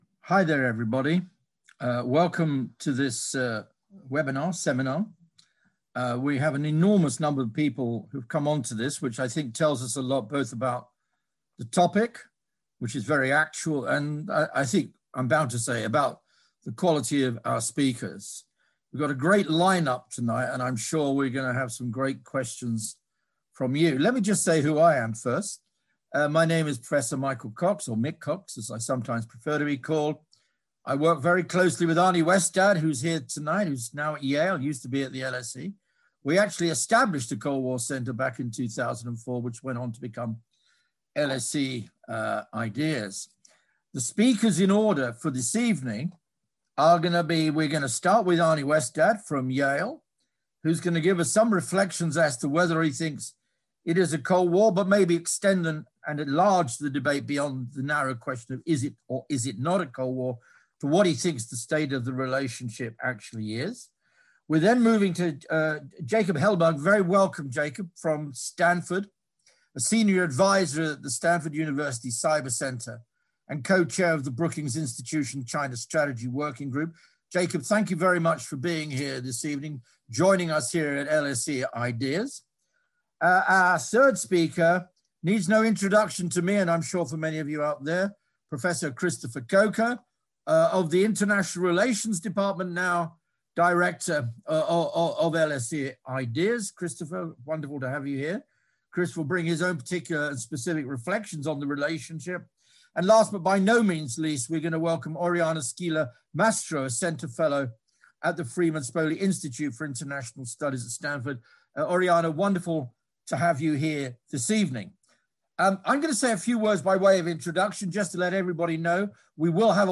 Listen to our panel discussion.